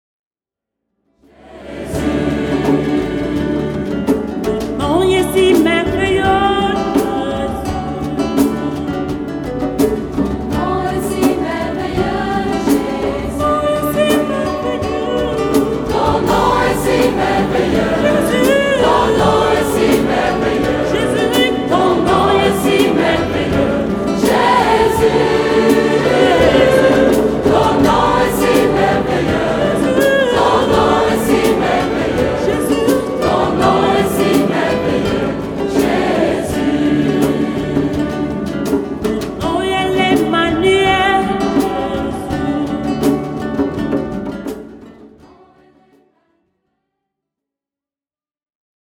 Enregistrement "live"
1ère partie : la chorale